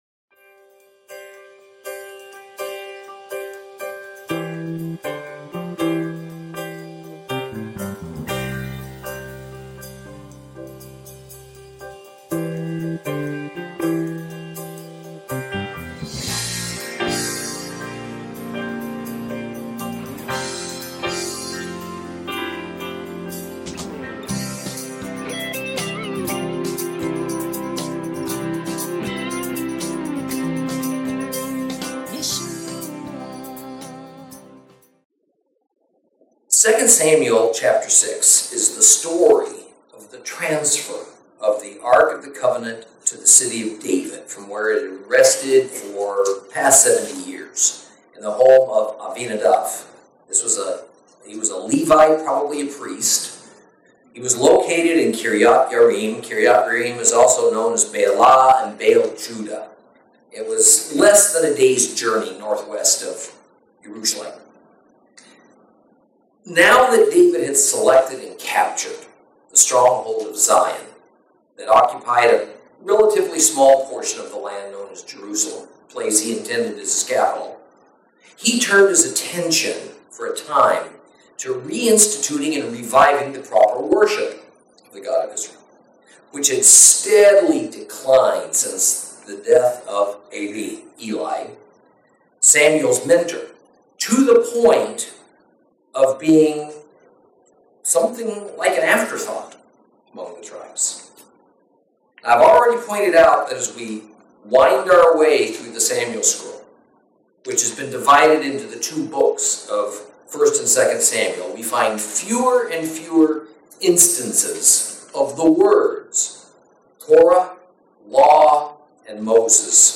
Lesson 9 – 2 Samuel 6 & 7